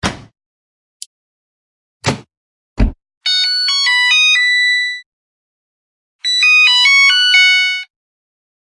洗衣房的声音 " 洗涤门打开
描述：老式投币式洗衣机的插销门被打开。一系列的事例。 以立体声、44.1khz、24bit在Tascam DR01便携式录音机上近距离录制.
标签： 机械 洗衣机 烘干机 SLAM